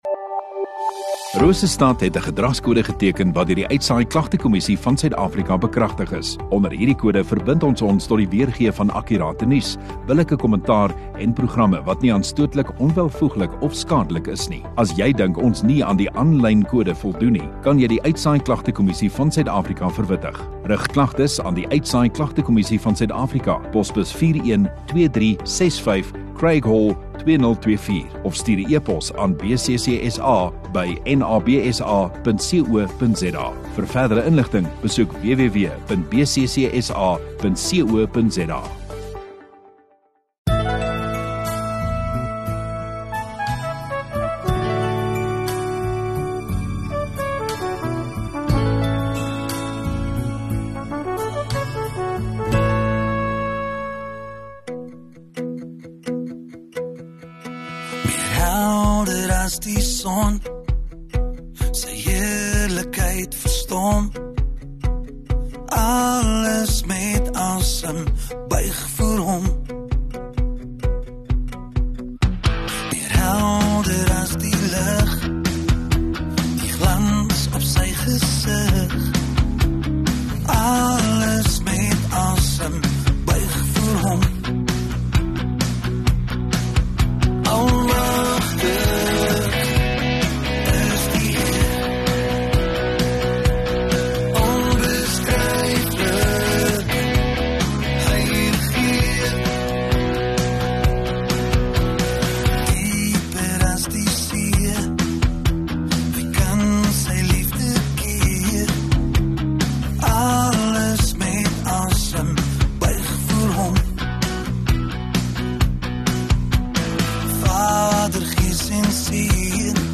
5 May Sondagoggend Erediens